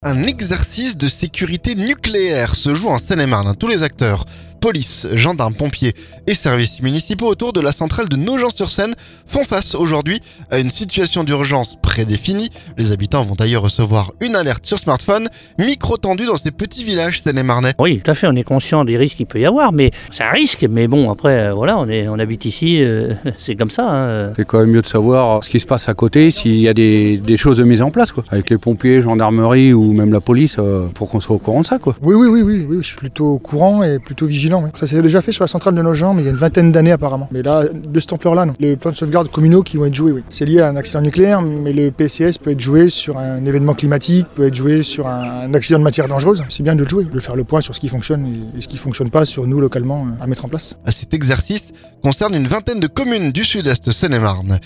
Micro tendu dans ces petits villages seine-et-marnais.